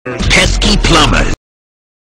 youtube-poop-sound-effect_-pesky-plumbers.mp3